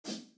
ShakerWolf.wav